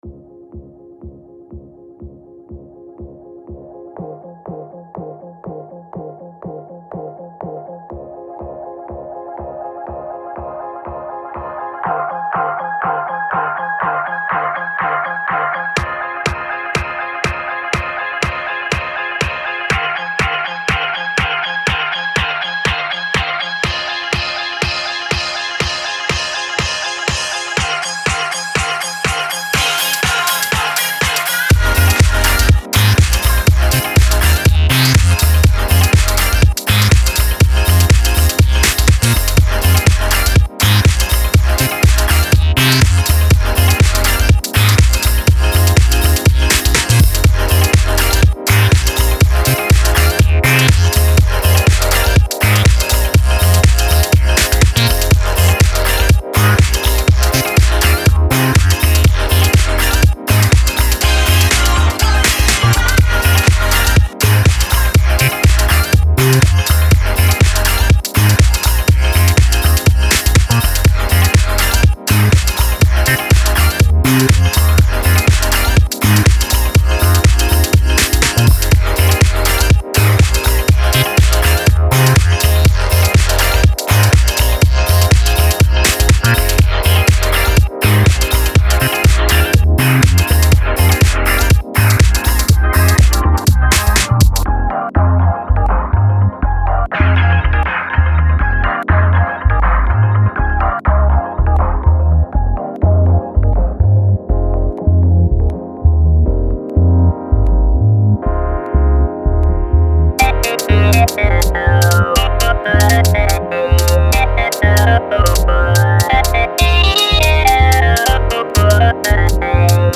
it’s more like black satin